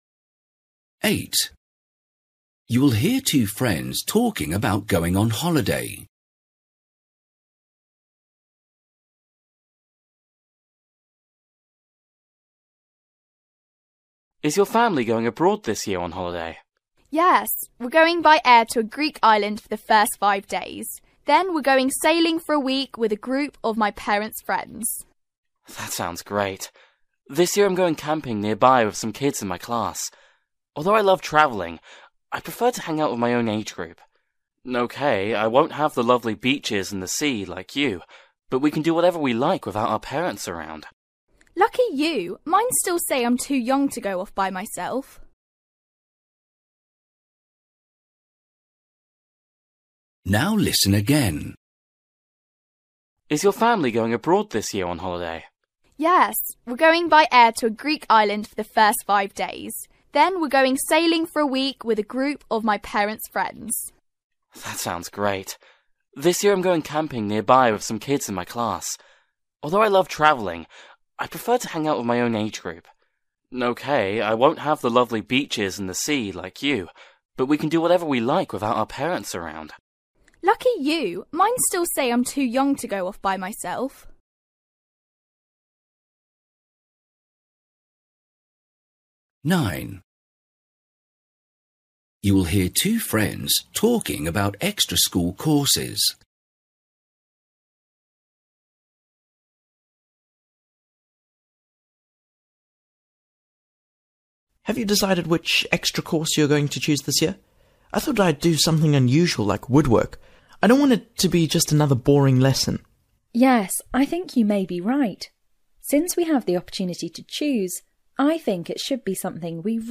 Listening: everyday short conversations
8   You will hear two friends talking about going on holiday. What does the boy like most?
10   You will hear a boy talking about a waterskiing weekend. How did he feel about it?